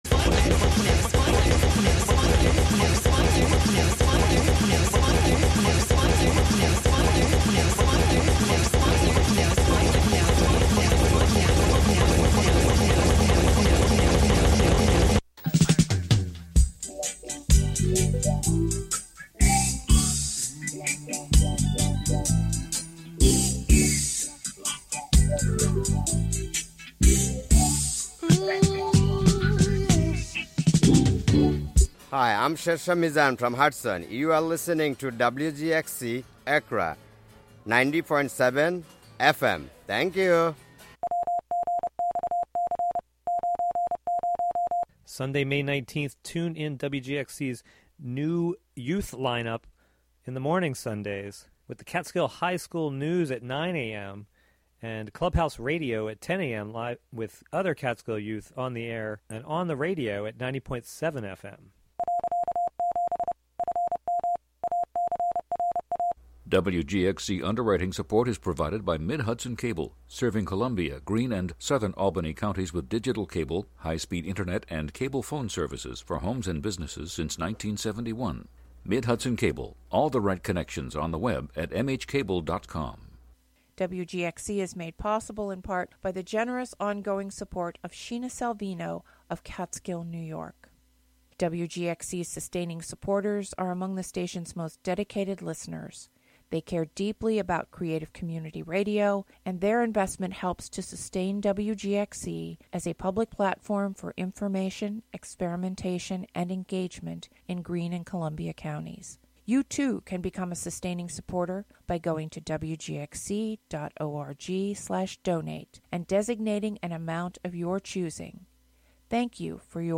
electronic sounds, field recordings and live mixing
live improvised alto and tenor saxophone